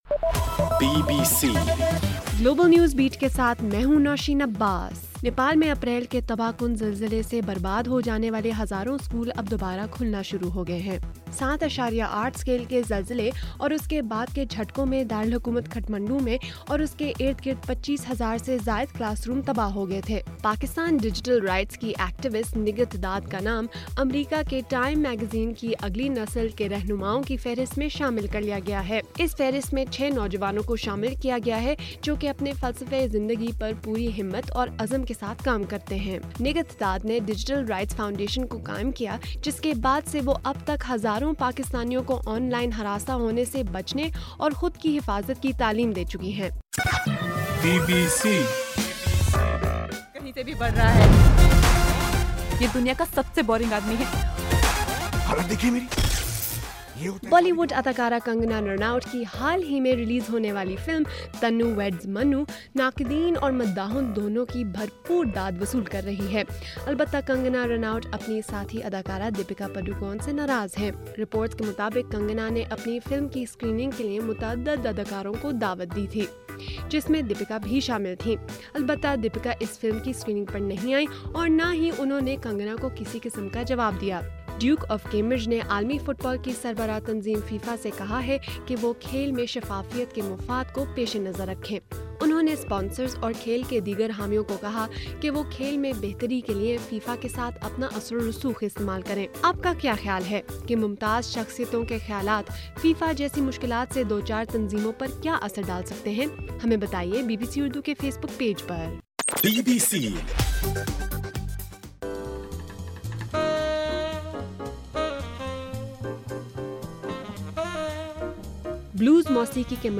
مئی 31: رات 8 بجے کا گلوبل نیوز بیٹ بُلیٹن